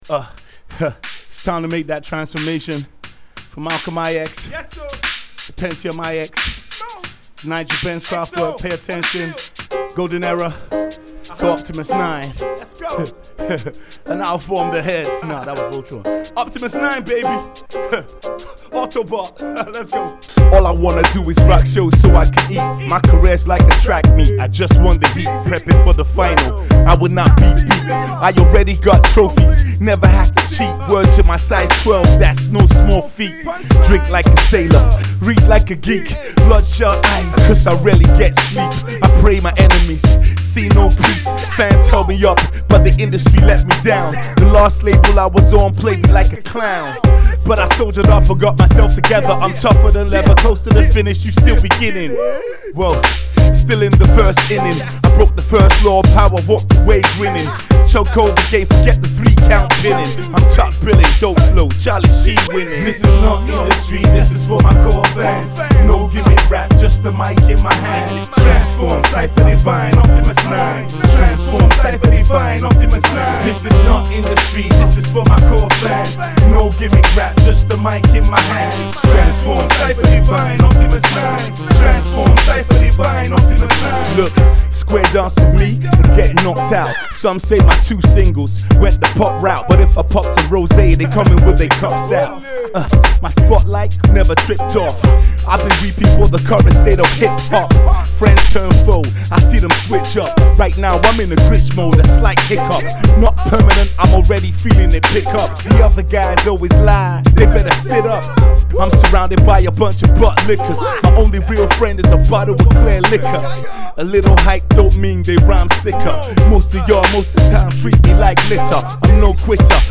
Hip-Hop
Legendary Nigerian MC